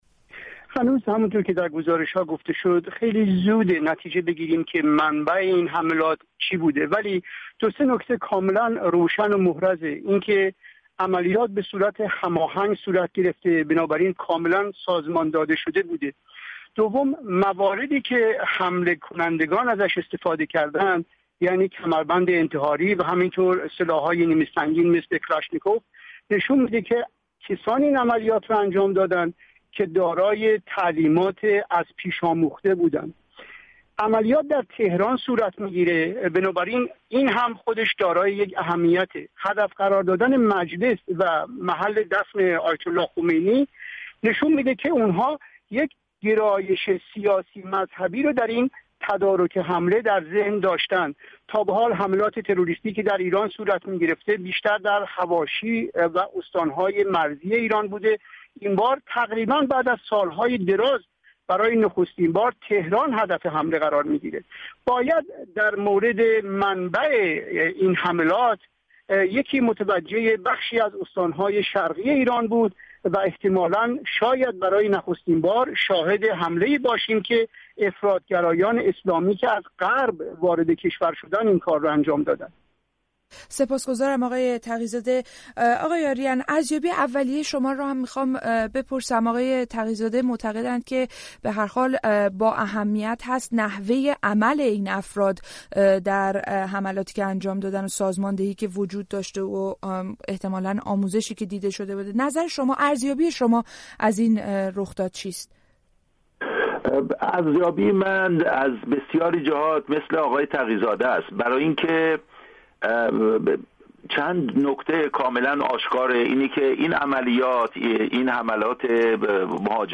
میزگردی با چهار تحلیلگر سیاسی و نظامی در مورد حمله به مجلس و آرامگاه خمینی